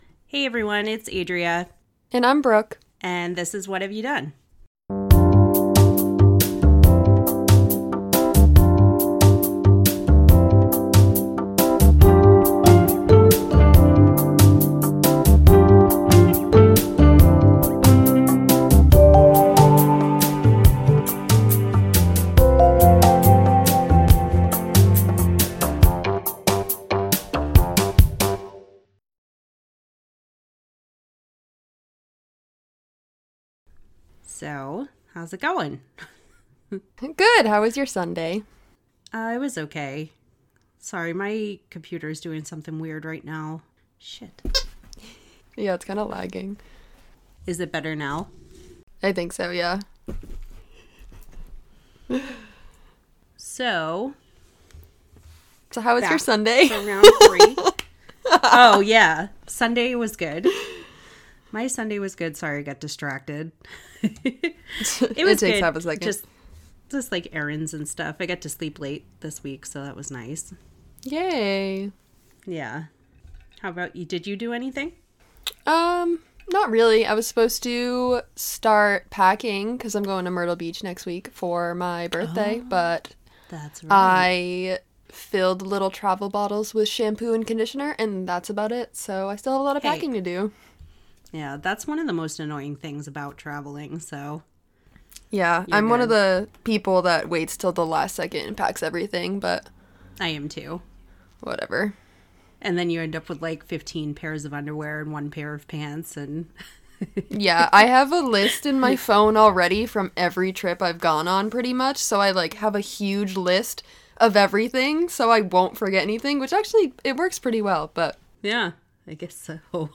Please bare with us with the little bit of overlap in audio in some places!